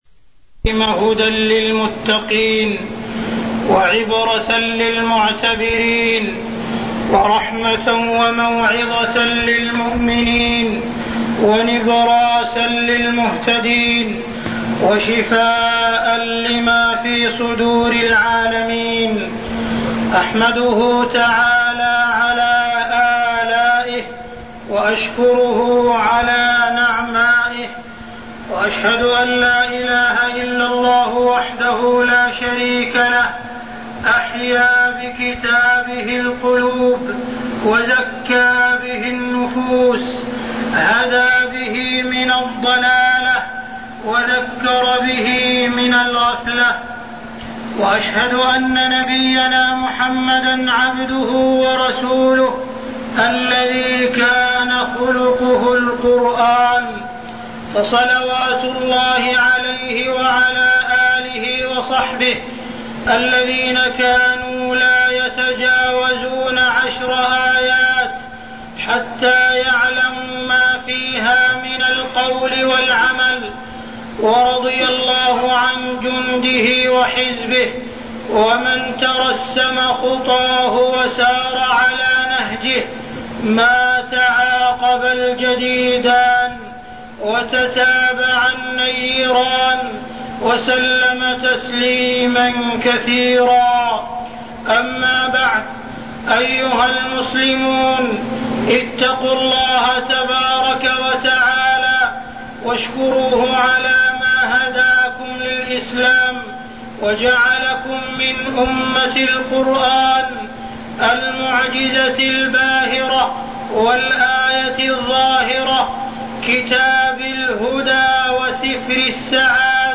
khotab-download-82087.htm